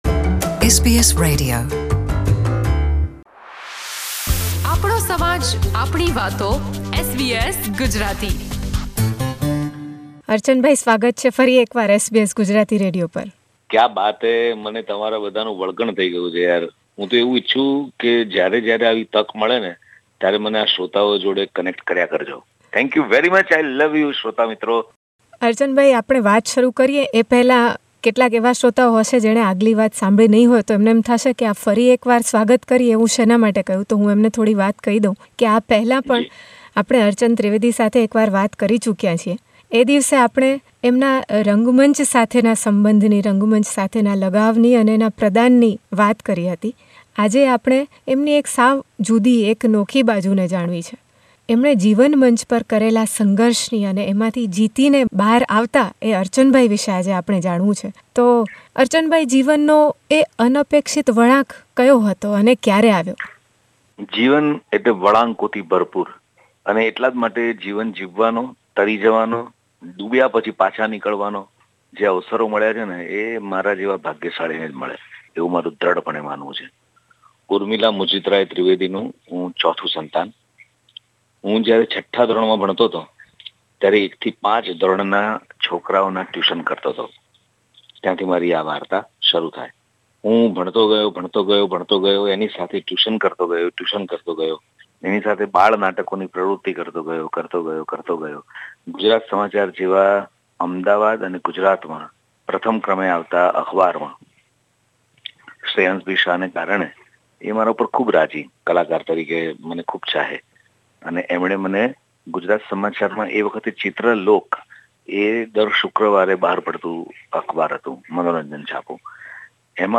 He talks about beating the odds with a big smile.